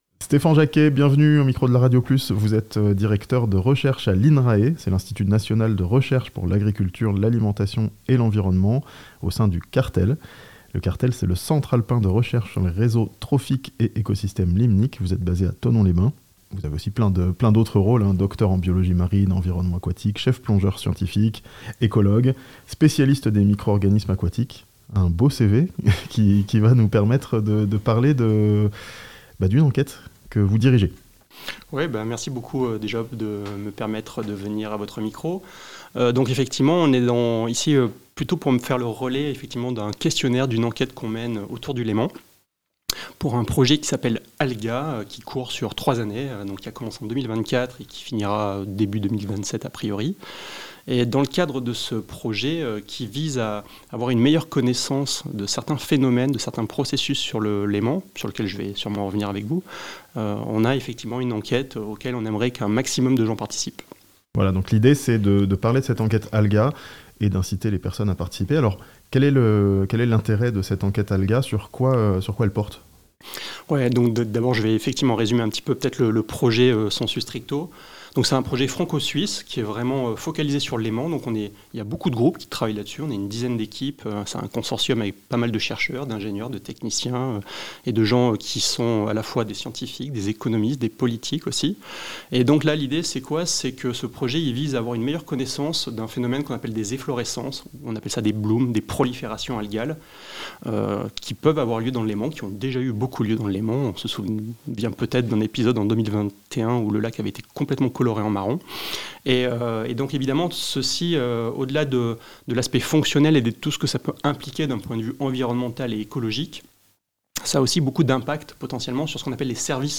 Dans le cadre d'un projet scientifique sur le Léman, l'INRAE vous invite à répondre à une enquête (interview)